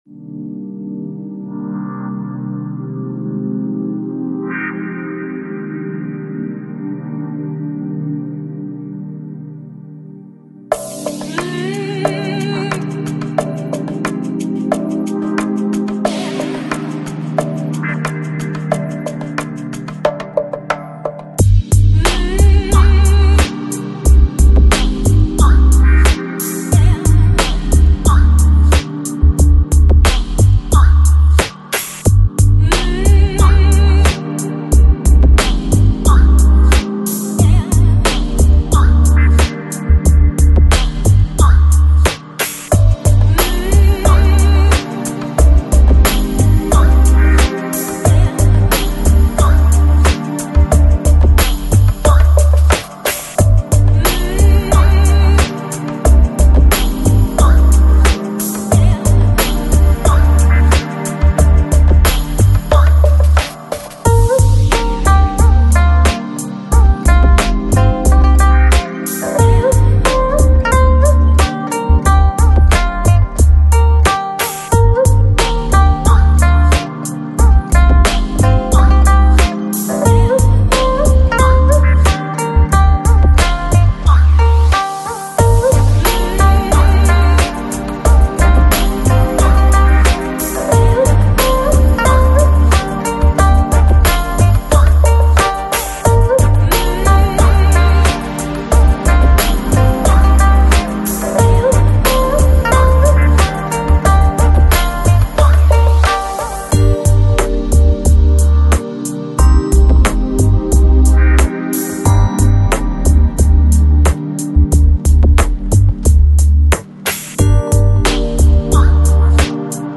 AAC Жанр: Lounge, Chill Out, Downtempo Продолжительность